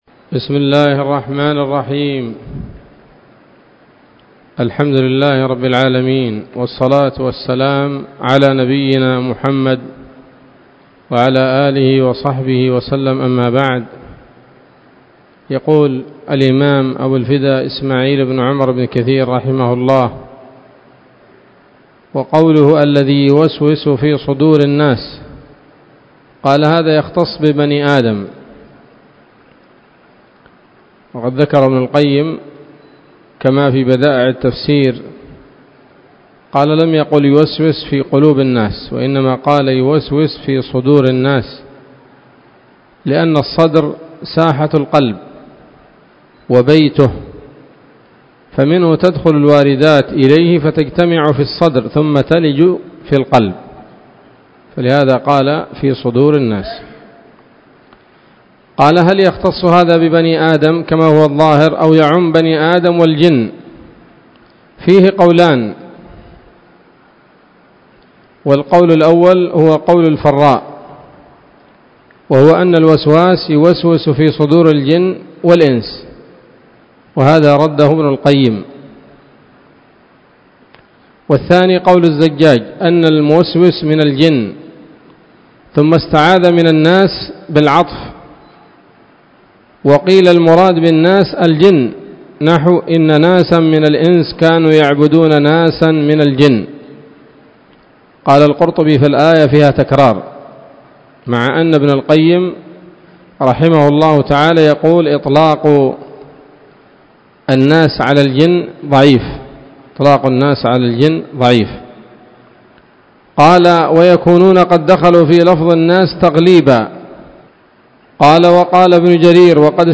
الدرس الثاني وهو الأخير من سورة الناس من تفسير ابن كثير رحمه الله تعالى